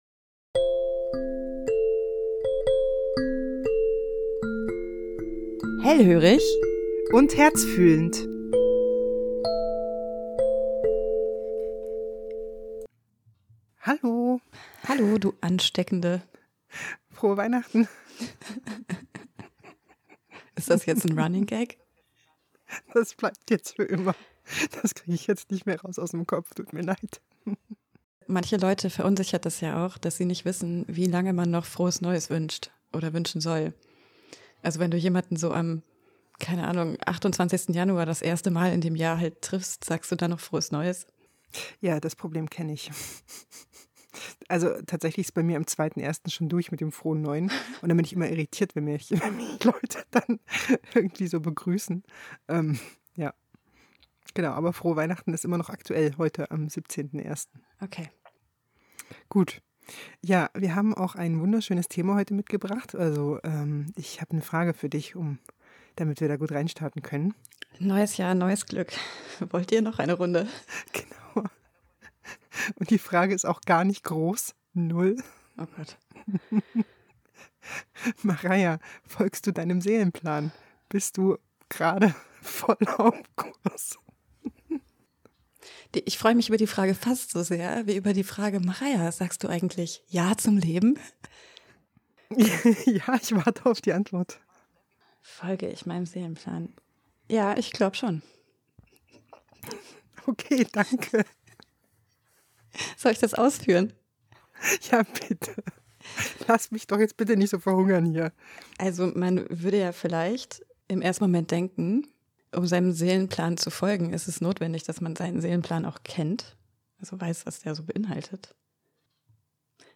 Wie ihr seht, geht es mal wieder locker leicht in unserem Podcaststudio zu.